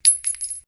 shellDrop5.wav